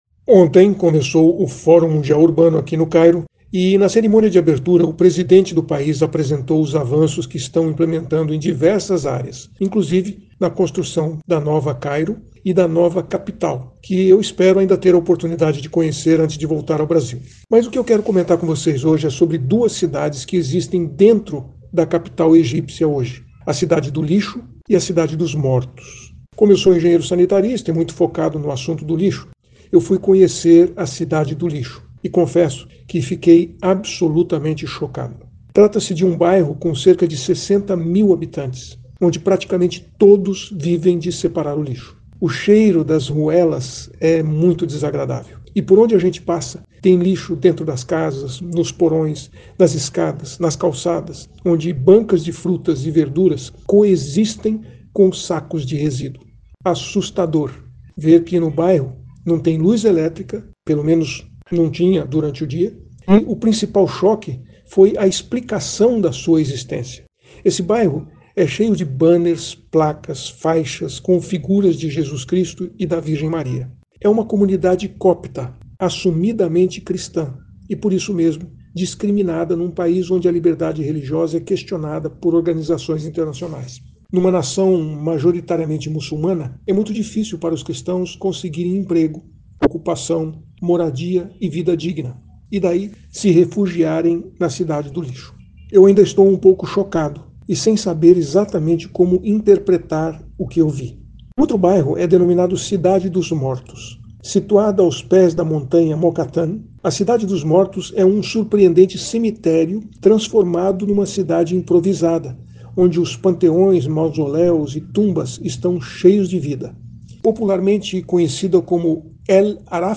CBN Cidadania e Sustentabilidade, com Silvio Barros, fala sobre atitudes sustentáveis feitos por instituições e pessoas.